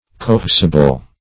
cohesible - definition of cohesible - synonyms, pronunciation, spelling from Free Dictionary
Cohesible \Co*he"si*ble\